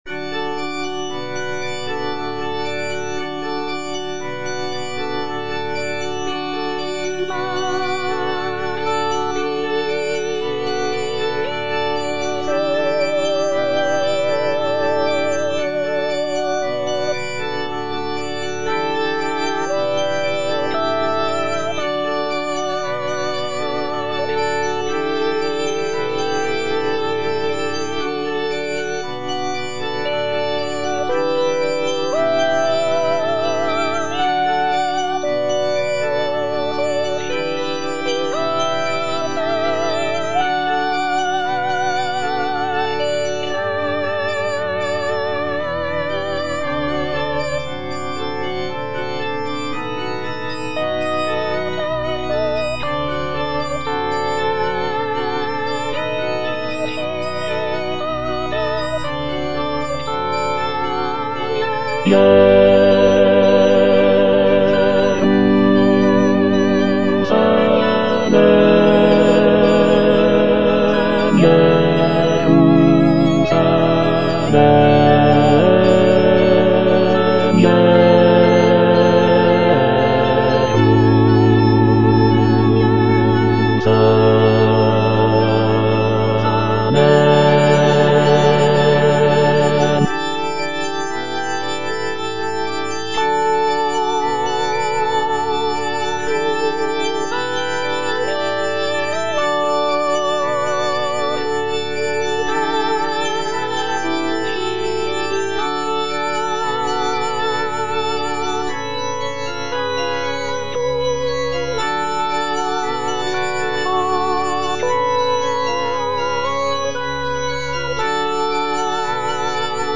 G. FAURÉ - REQUIEM OP.48 (VERSION WITH A SMALLER ORCHESTRA) In paradisum (bass I) (Emphasised voice and other voices) Ads stop:
Gabriel Fauré's Requiem op. 48 is a choral-orchestral work that is known for its serene and intimate nature.
This version features a reduced orchestra with only a few instrumental sections, giving the work a more chamber-like quality.